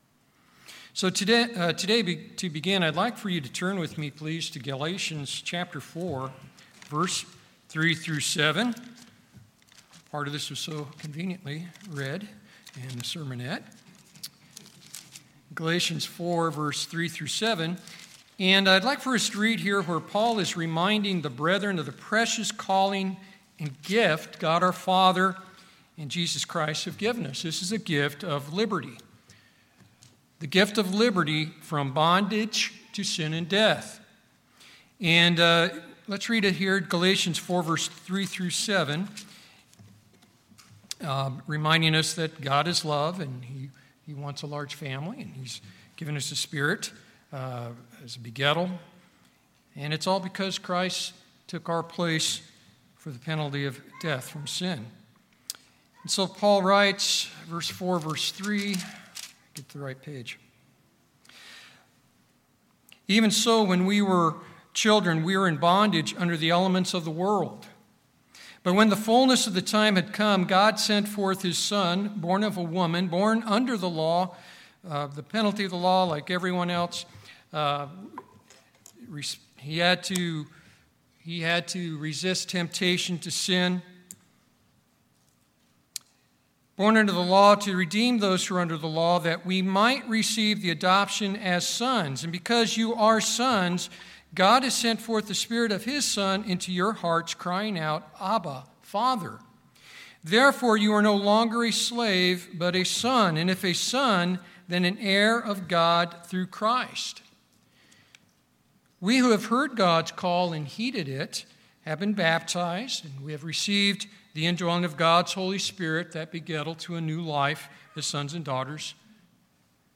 In this sermon we are reminded that God has called us and placed us in the Body of Christ as He pleases, and He requires that we as brethren serve one another through love -- love of God. This message encourages us to become even more effective in serving one another and so endure to the end and be found worthy of receiving the gift of everlasting life at Christ's return.